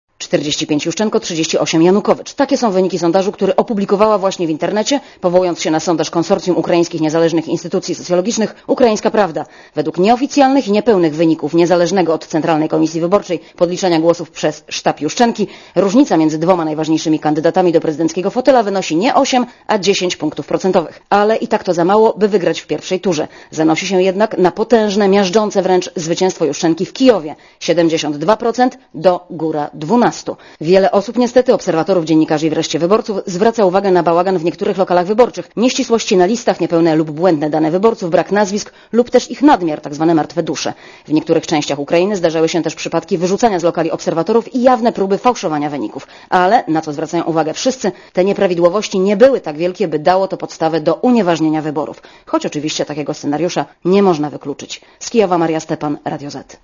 specjalnej wysłanniczki Radia ZET do Kijowa*